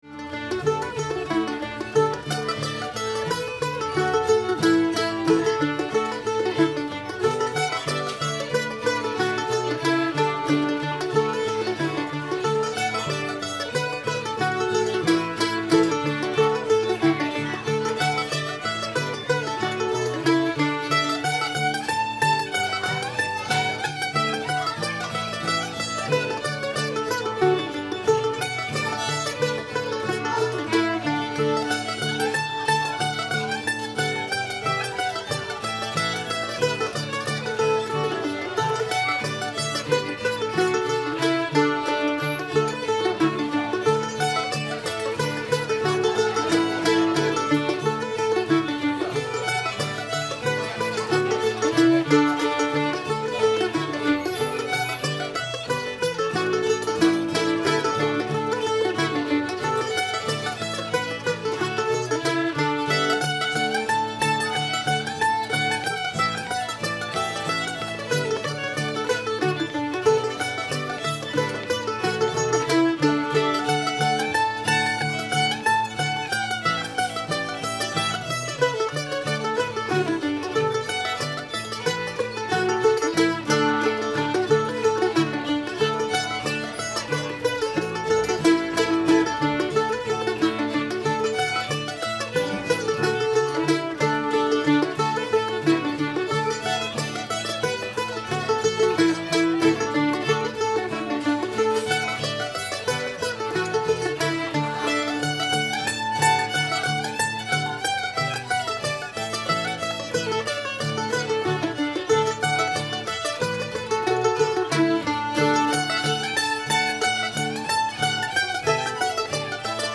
grand picnic [D]